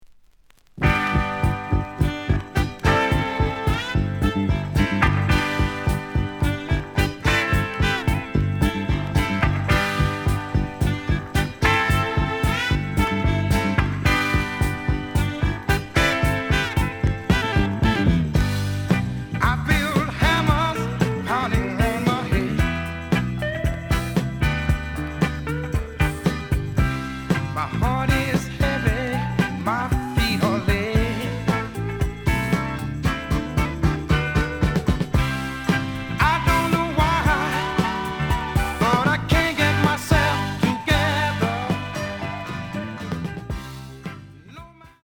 The audio sample is recorded from the actual item.
●Genre: Disco
Slight affect sound.